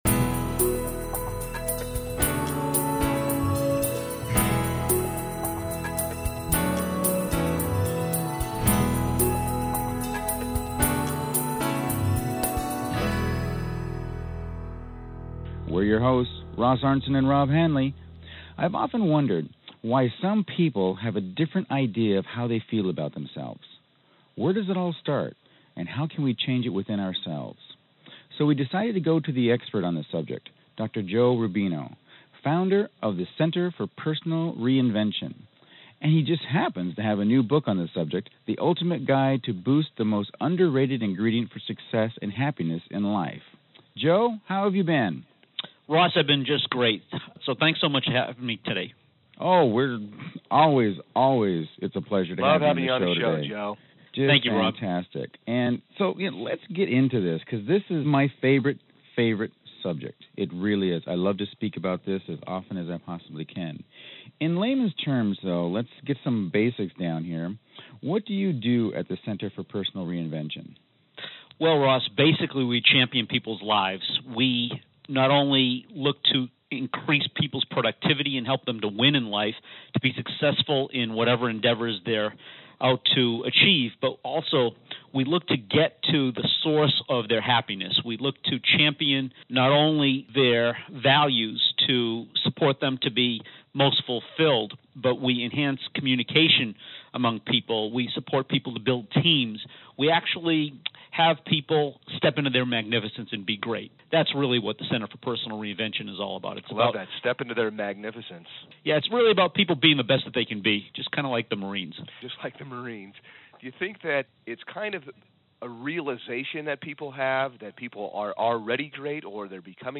Behind Closed Doors Success Interview